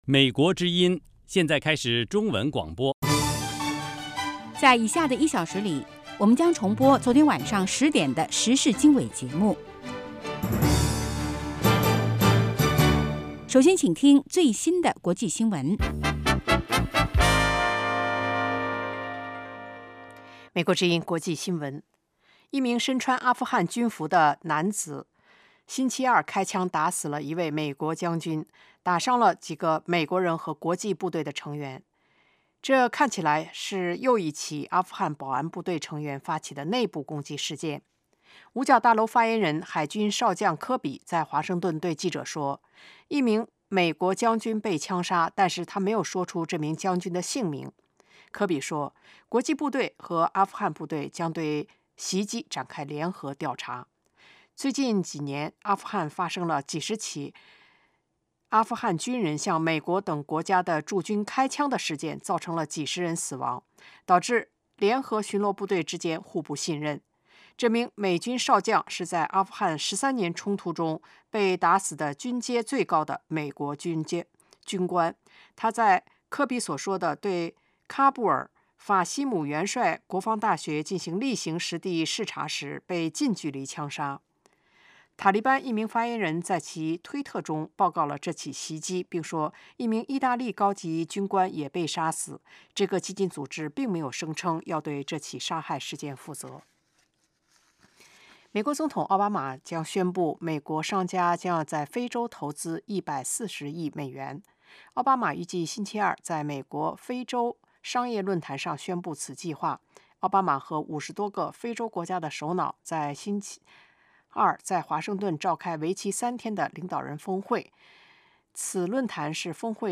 早6-7点广播节目